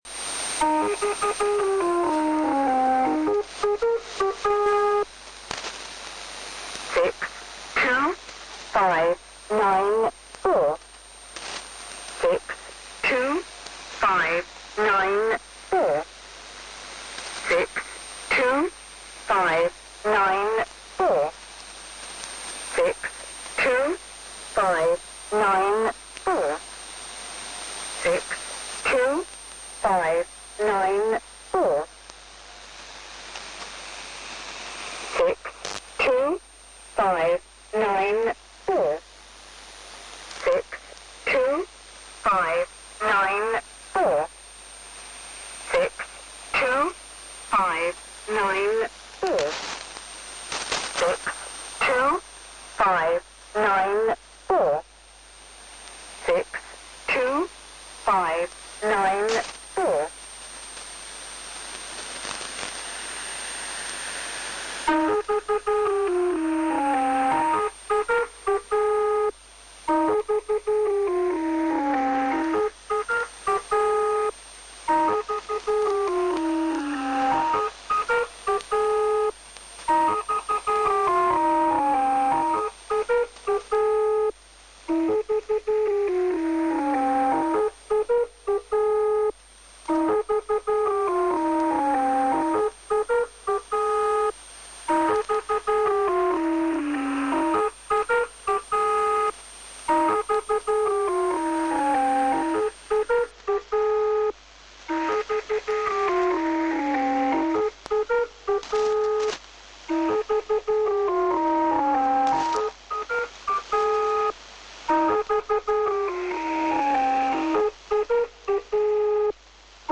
Начало » Записи » Номерные станции